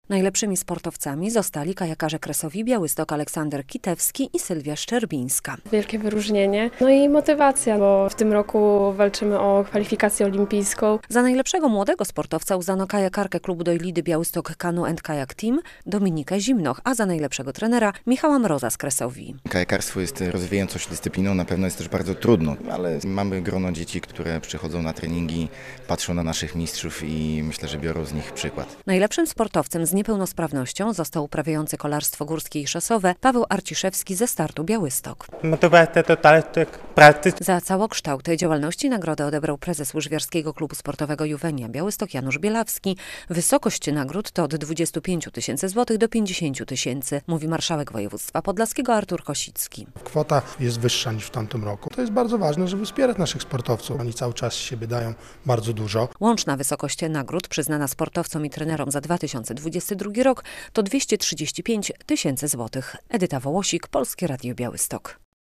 Nagrody sportowe zarządu województwa podlaskiego za 2022 rok - relacja